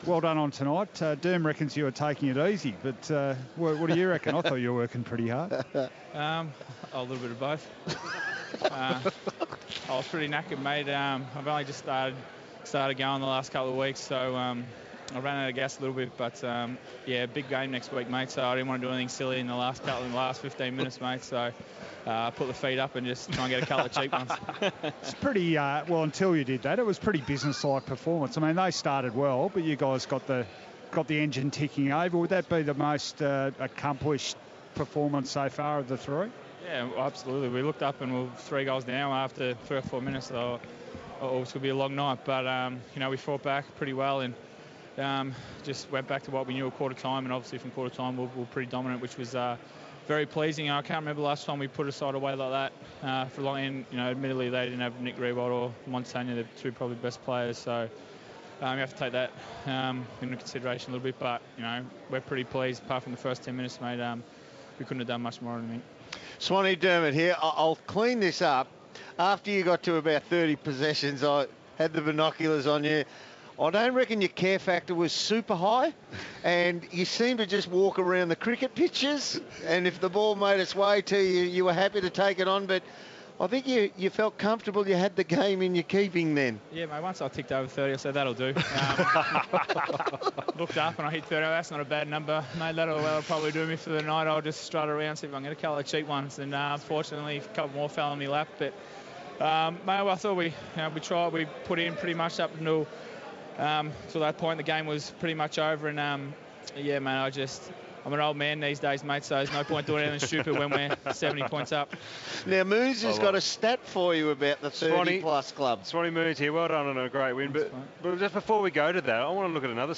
Collingwood midfielder Dane Swan chats with 1116 SEN footy team after the Pies 74-point win over St Kilda.